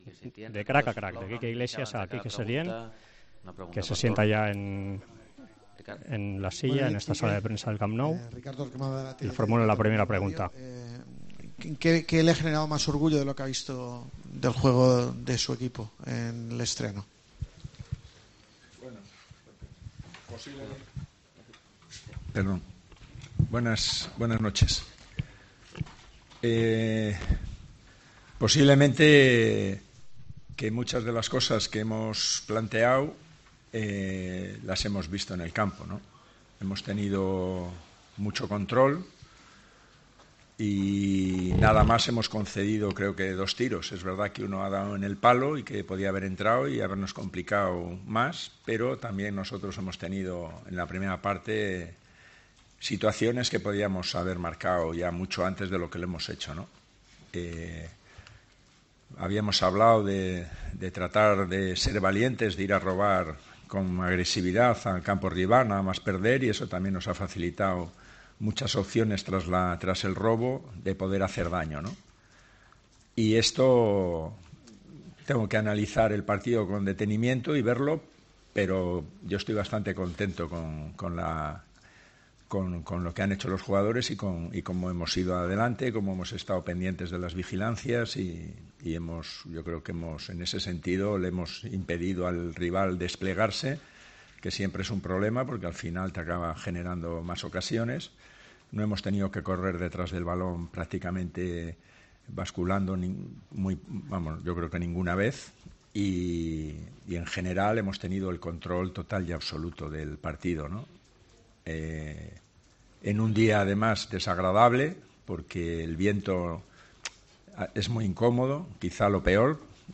El nuevo entrenador del Barcelona, Quique Setién, sale satisfecho de su primer partido al frente del equipo azulgrana, este domingo, ante Granada, tal como dio a entender en la rueda de prensa posterior al encuentro: "Me voy satisfecho porque muchas de las cosas que hemos planteado las hemos visto en el campo.